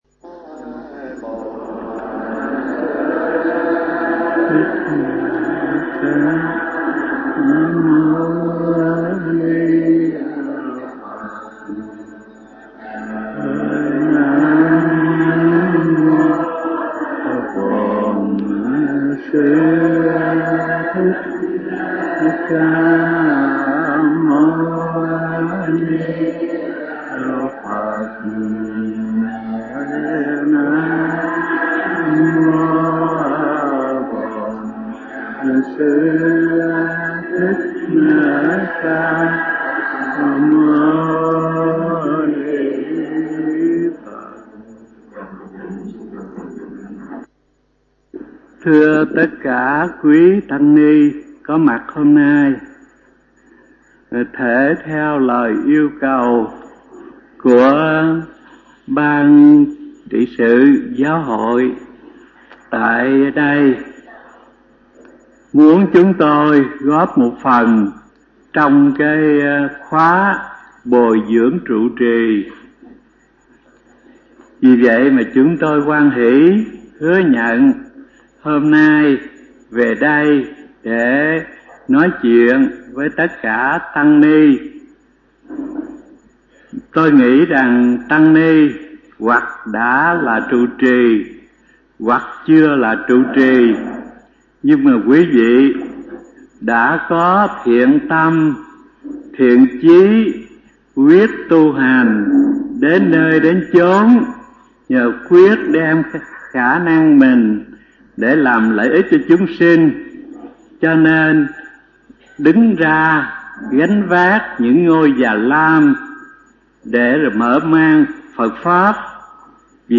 bài giảng